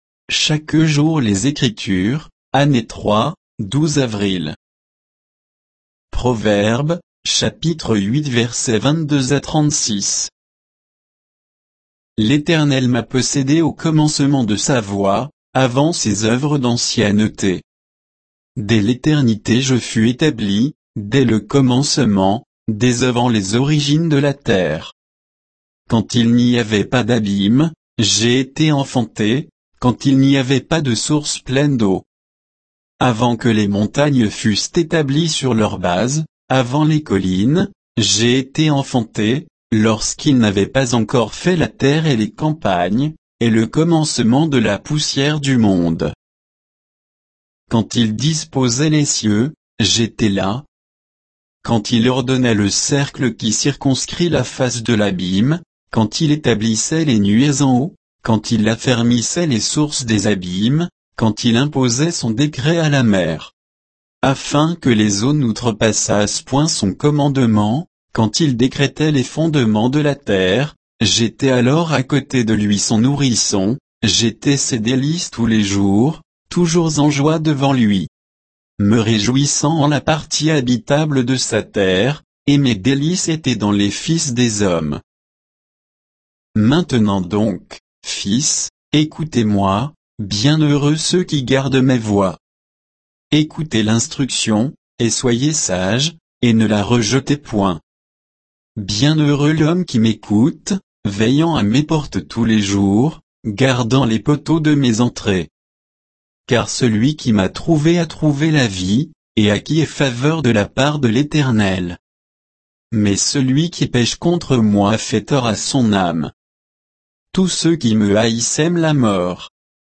Méditation quoditienne de Chaque jour les Écritures sur Proverbes 8, 22 à 36